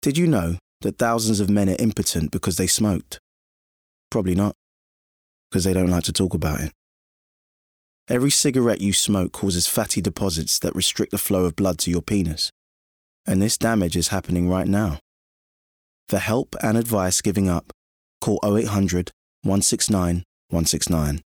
20/30's London, Fresh/Funny/Charismatic